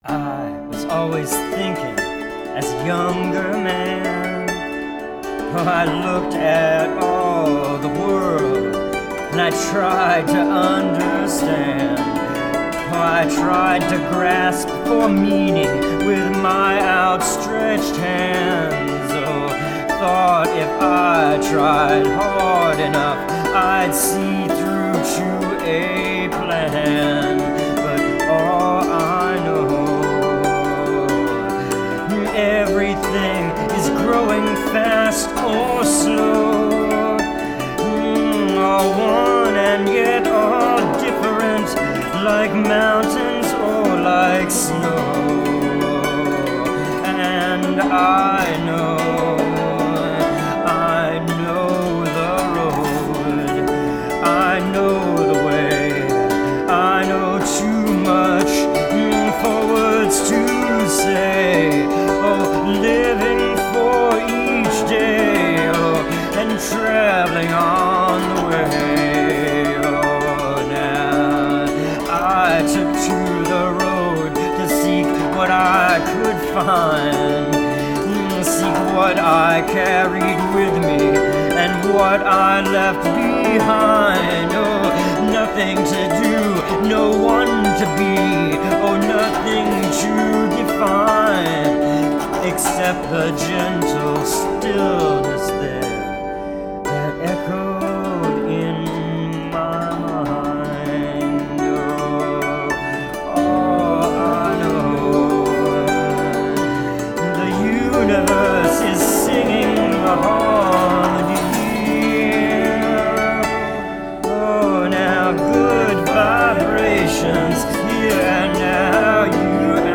Solo melody and harmony Vocals and Acoustic Dulcimer